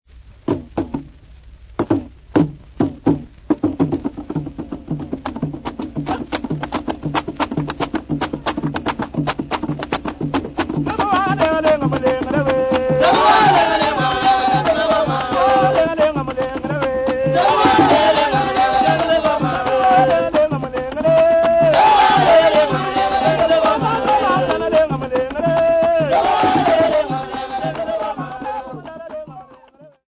Kasai men and women
Popular music--Africa
Field recordings
sound recording-musical
Katela funeral dance song accompanied by 2 drums conical, pegged, single and open